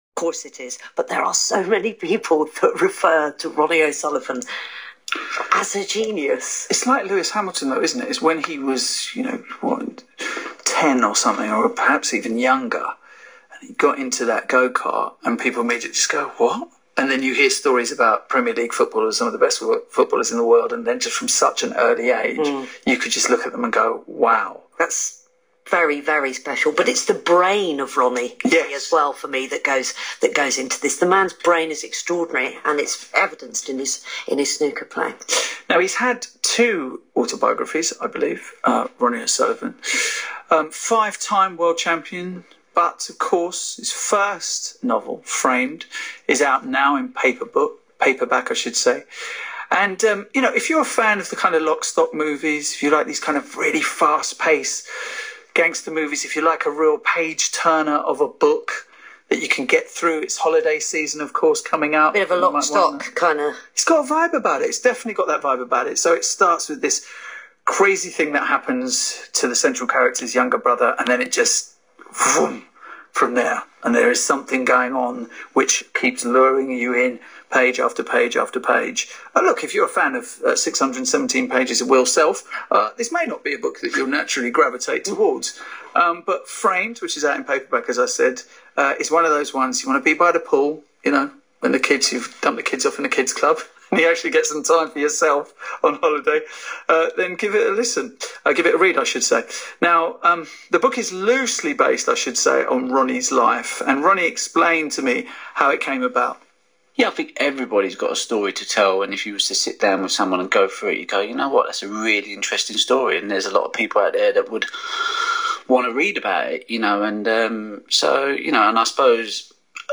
A very, very nice interview with Ronnie
The snippet I published yesterday is part of a nearly 20 minutes interview with Ronnie on Radio 5.